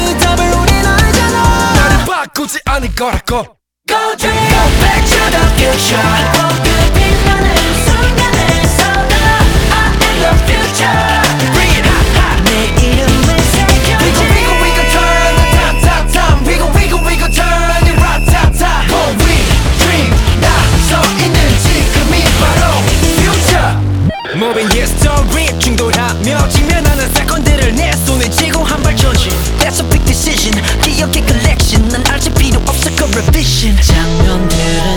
Скачать припев
K-Pop Pop
2025-07-14 Жанр: Поп музыка Длительность